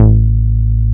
R MOOG A2MF.wav